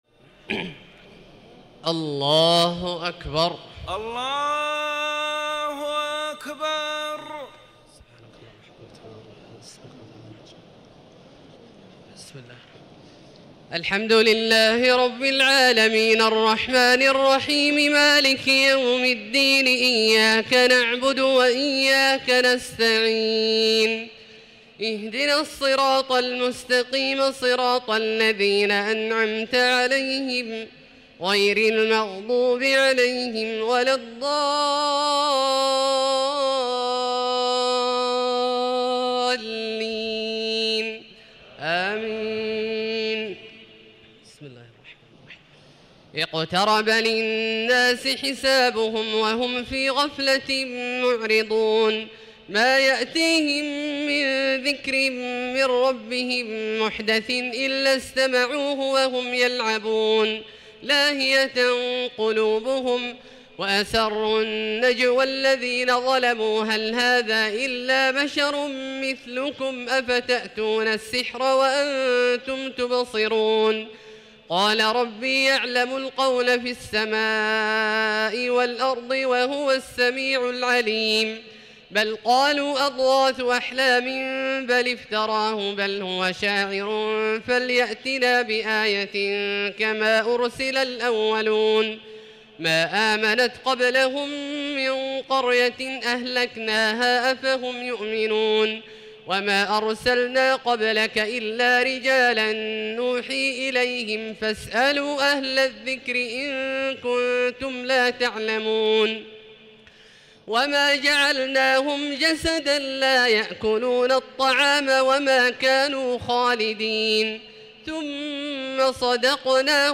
تراويح ليلة 30 رمضان 1437هـ سورة الأنبياء كاملة Taraweeh 30 st night Ramadan 1437H from Surah Al-Anbiyaa > تراويح الحرم المكي عام 1437 🕋 > التراويح - تلاوات الحرمين